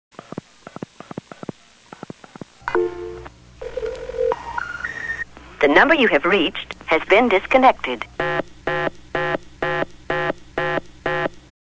% Listening to result, tones are largely suppressed
% Notice how the DTMF tones are completely eliminated, leading to
However, if the tones are not entirely stable, they will not be completely eliminated.